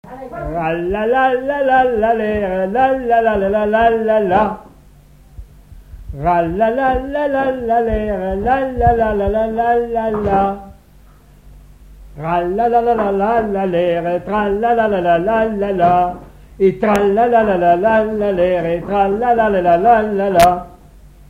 Enumératives - Nombres en décroissant
Pièce musicale inédite